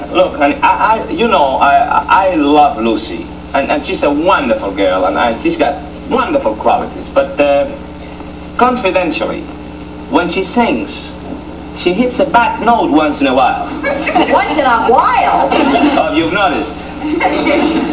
(387 kb)- Ricky telling Ethel how he thinks Lucy hits a wrong note "once in a while!!!???"